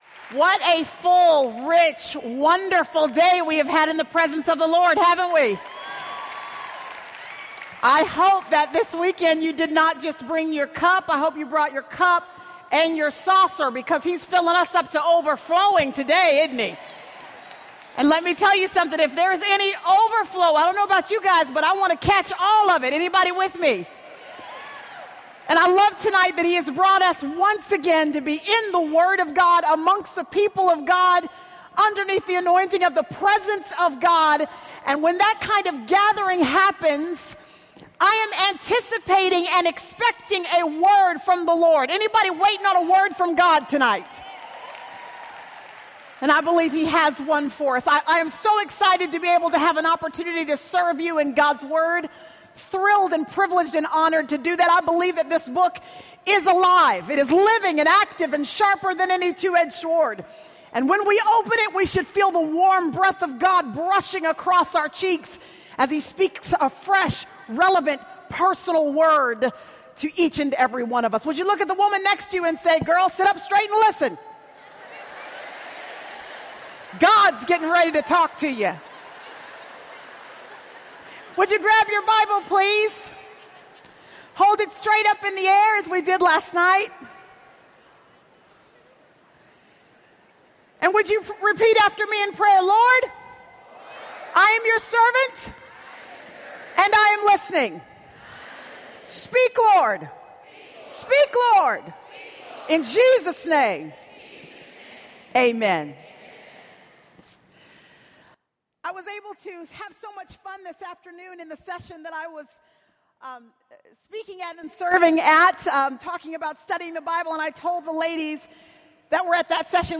| True Woman '12 | Events | Revive Our Hearts